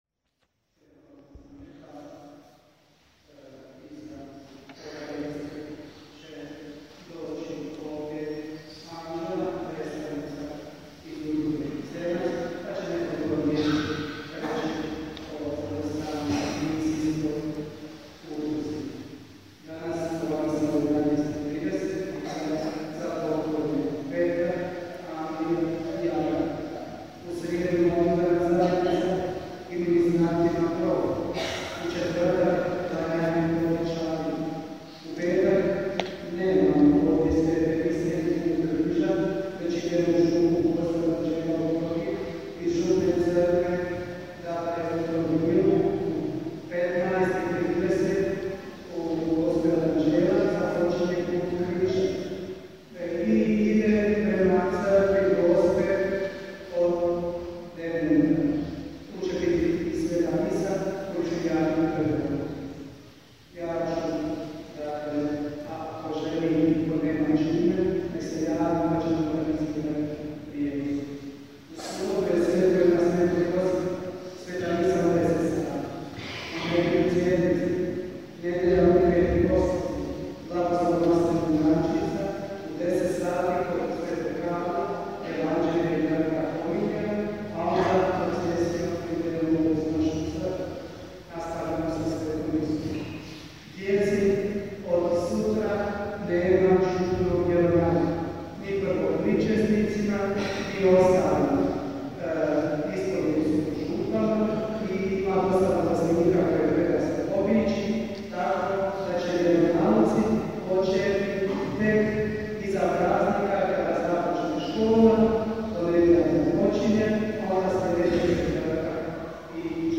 OBAVIJESTI i BLAGOSLOV: :
OBAVIJESTI I BLAGOSLOV